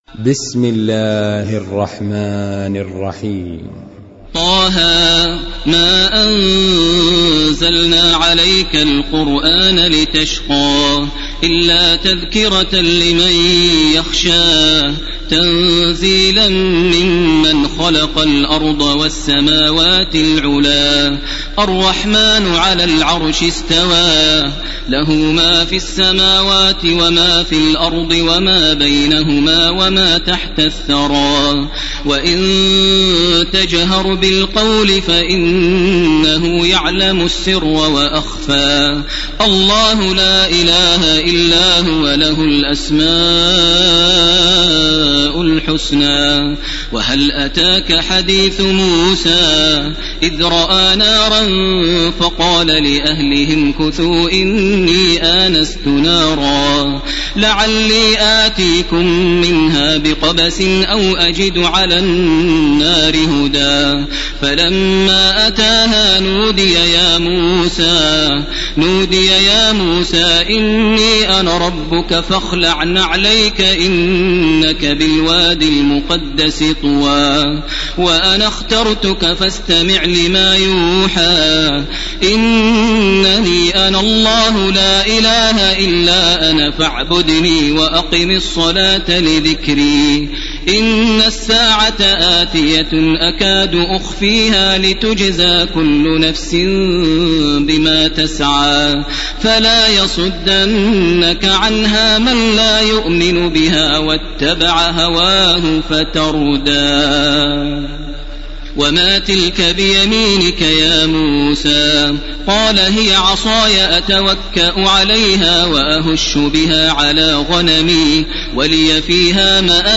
تراويح الليلة السادسة عشر رمضان 1431هـ سورة طه كاملة Taraweeh 16 st night Ramadan 1431H from Surah Taa-Haa > تراويح الحرم المكي عام 1431 🕋 > التراويح - تلاوات الحرمين